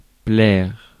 Ääntäminen
Synonyymit botter taper dans l'œil tomber dans l'œil Ääntäminen France: IPA: [plɛʁ] Haettu sana löytyi näillä lähdekielillä: ranska Käännöksiä ei löytynyt valitulle kohdekielelle.